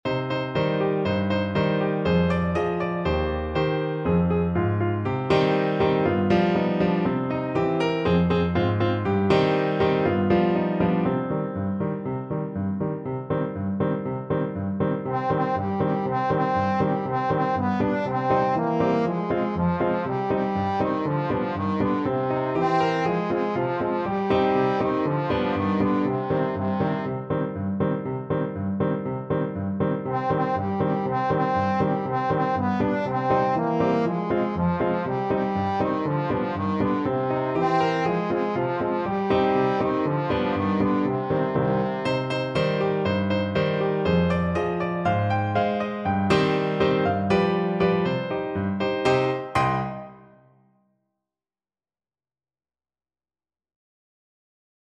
2/4 (View more 2/4 Music)
Allegro (View more music marked Allegro)
Classical (View more Classical Trombone Music)